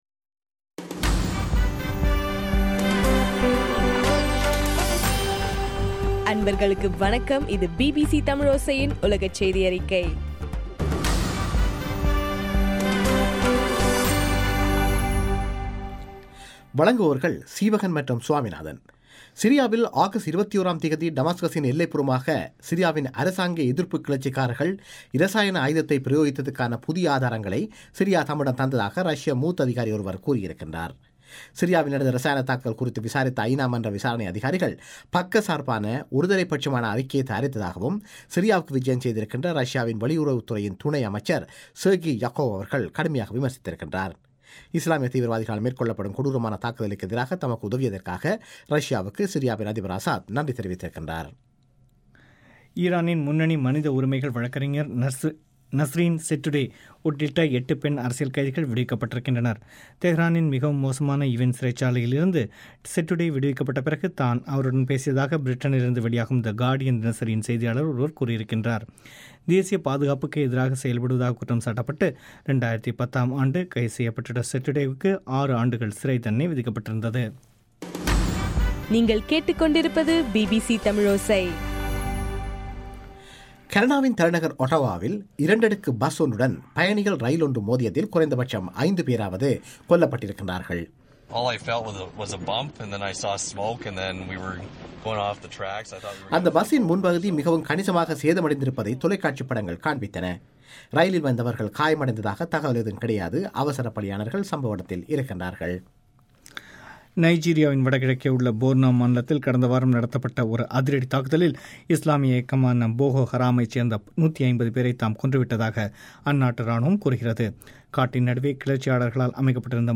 செப்டம்பர் 18 பிபிசியின் செய்தியறிக்கை